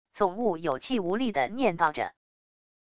总悟有气无力地念叨着wav音频